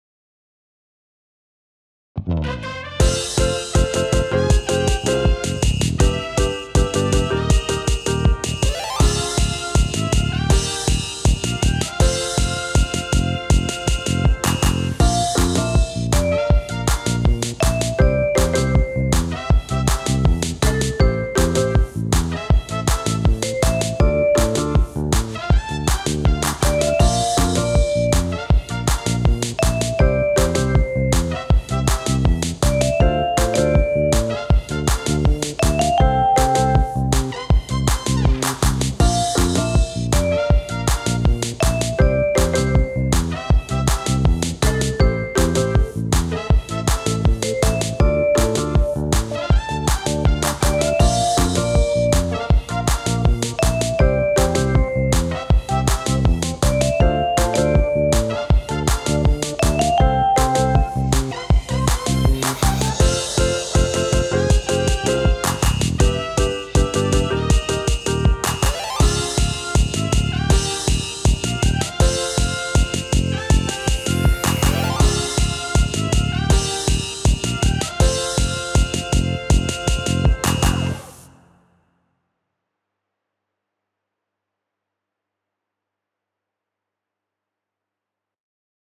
ポップ明るい激しい
BGM